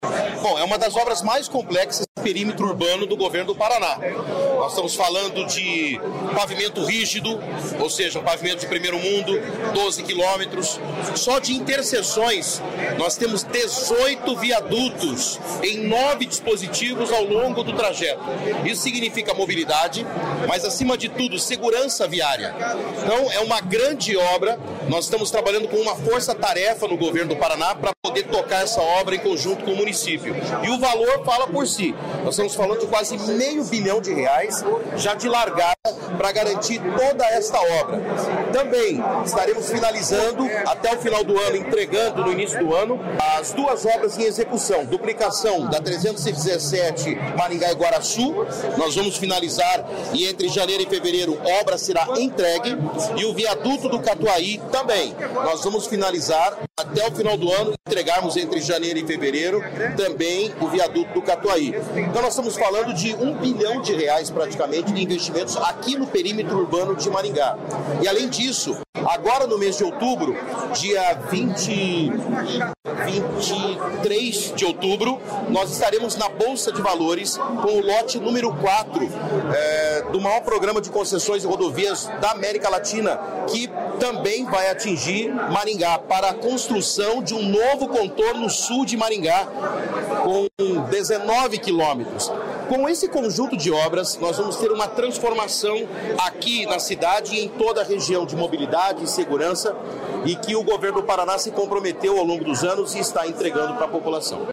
Sonora do secretário de Infraestrutura e Logística, Sandro Alex, sobre convênio da duplicação do Contorno Sul de Maringá